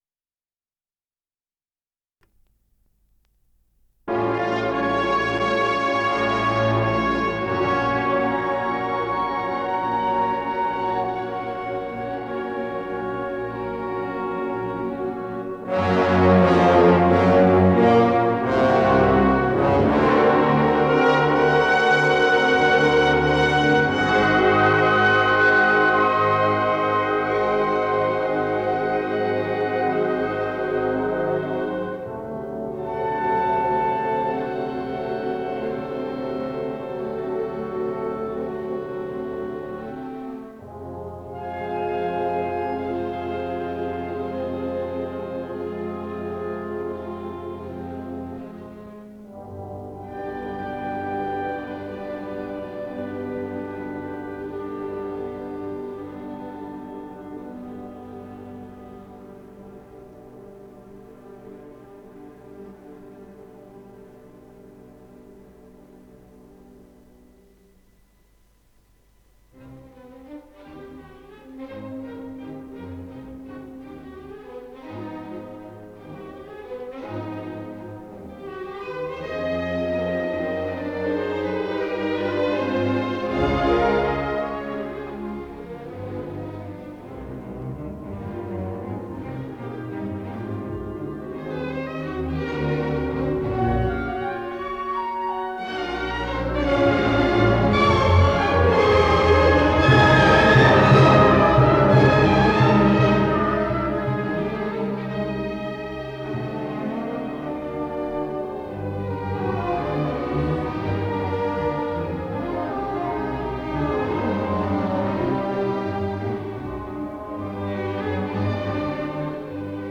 Исполнитель: Большой симфонический оркестр Всесоюзного радио и Центрального телевидения
Третья симфония, до мажор